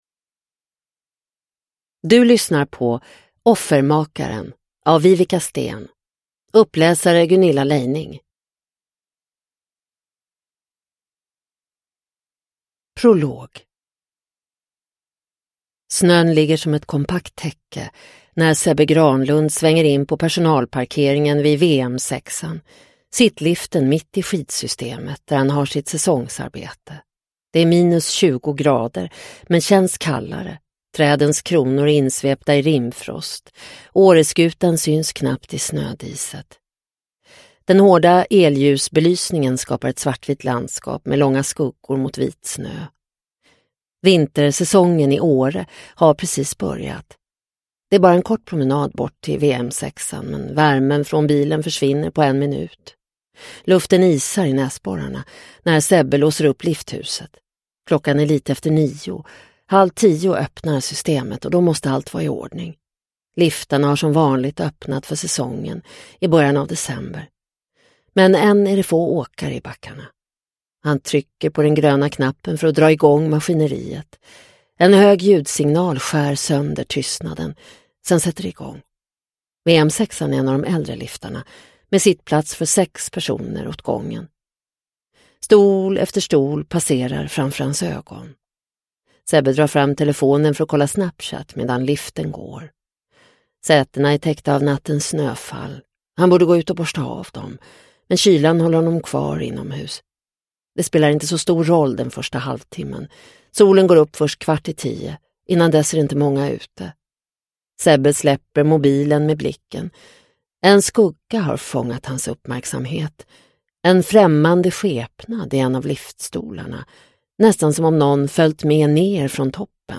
Offermakaren – Ljudbok – Laddas ner